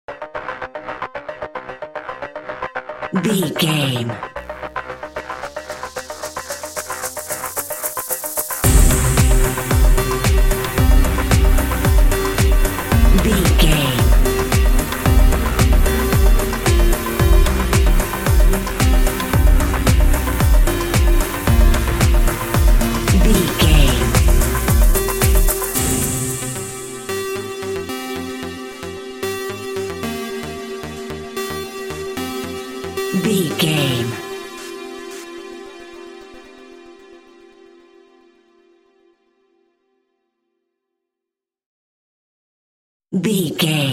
Aeolian/Minor
groovy
uplifting
driving
energetic
repetitive
synthesiser
drum machine
house
techno
trance
synth leads
synth bass
upbeat